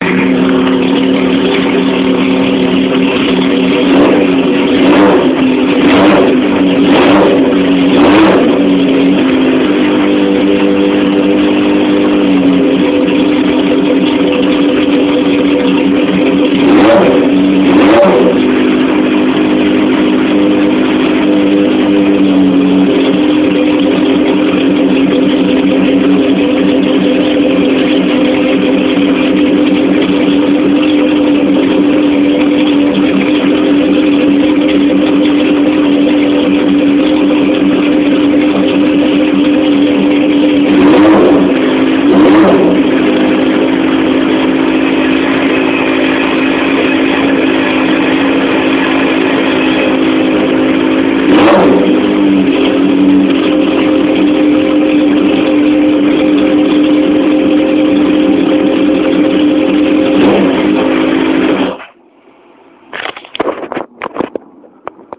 Vroom
dunt that sound well :lol:
That sounds really sweet.